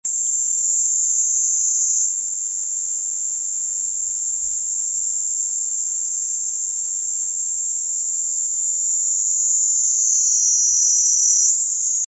robust cicada